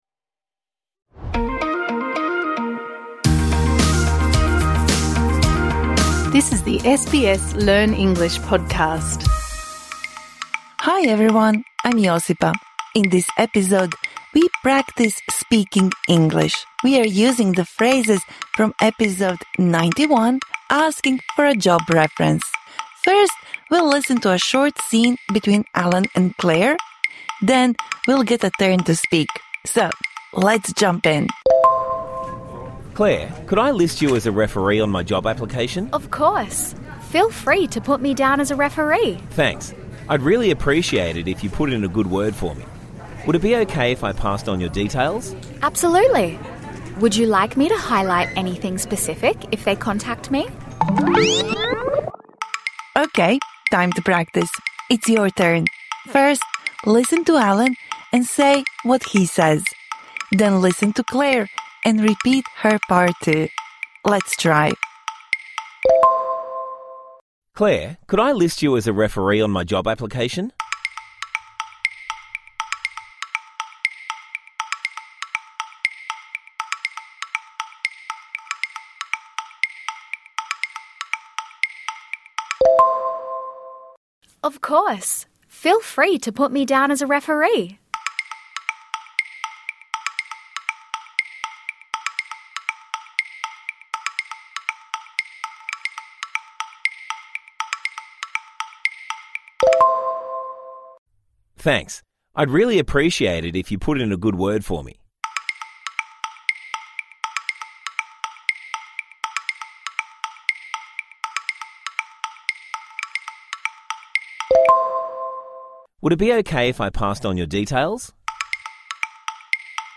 This bonus episode provides interactive speaking practice for the words and phrases you learnt in Episode #91 Asking for a job reference (Adv).